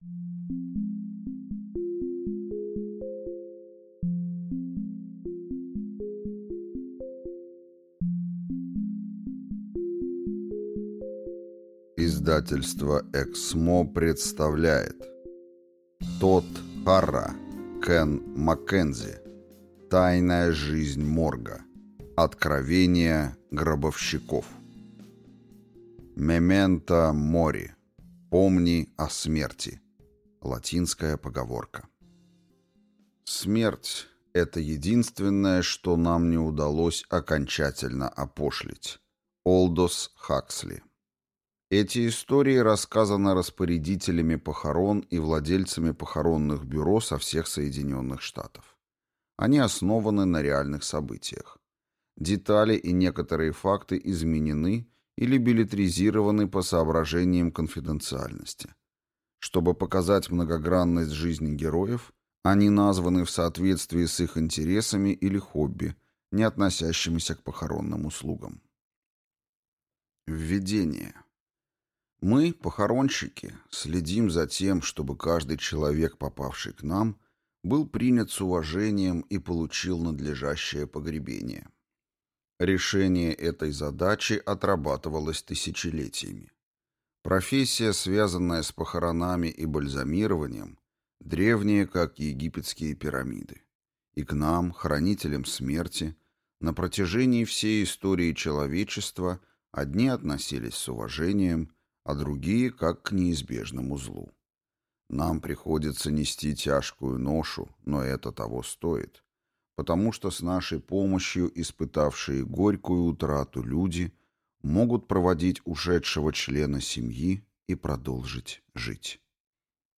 Аудиокнига Тайная жизнь морга. Откровения гробовщиков | Библиотека аудиокниг